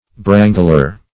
Brangler \Bran"gler\, n. A quarrelsome person.